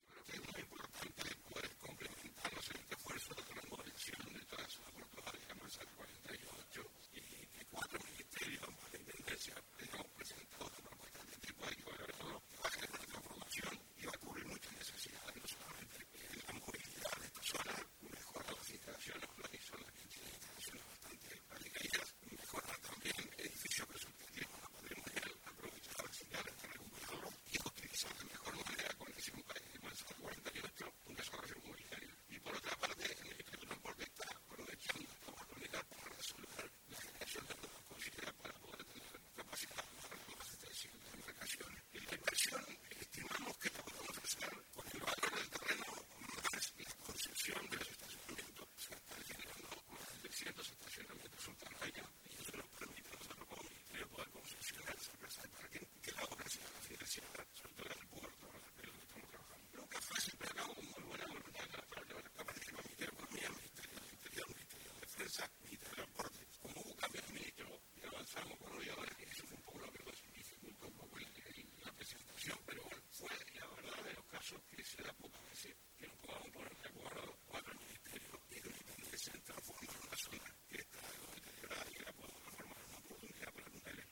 El ministro de Transporte y Obras Públicas, José Luis Falero destacó la importancia de la remodelación para Punta del Este